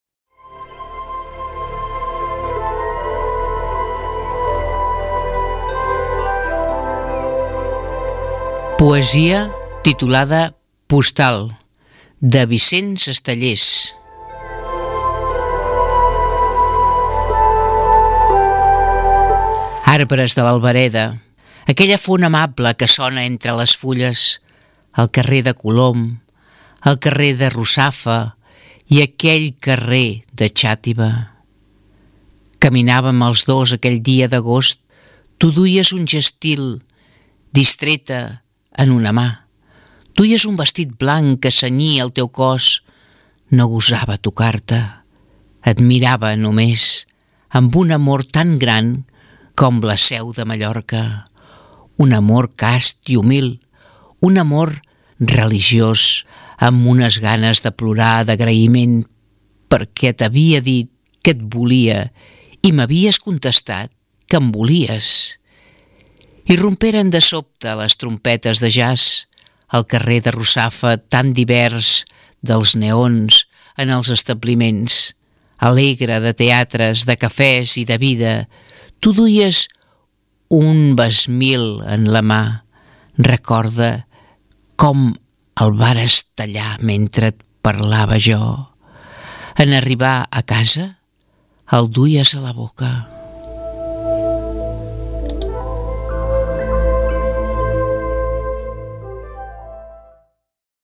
Espai de poesia: Postal, de Vicent Andrés Estellés - Ràdio Tordera, 2021